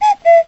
cuckoo.wav